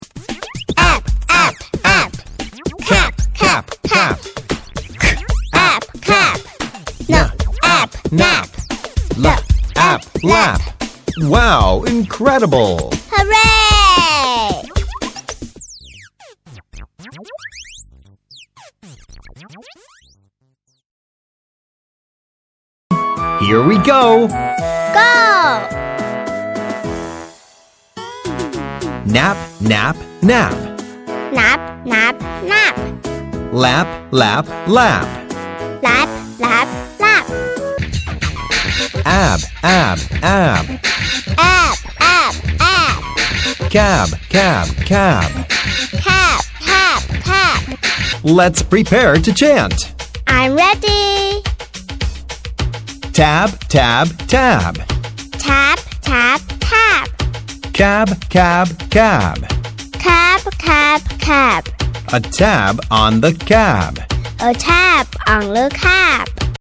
子どもが復唱している声も収録されていますので、音声を聞く子どもにとっても親しみやすいこと間違いなしです。
チャンツはリズムよく、短く、復唱しやすいものばかり。
母音＋子音のペアが復習できるチャンツが付いています。